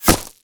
bullet_impact_gravel_07.wav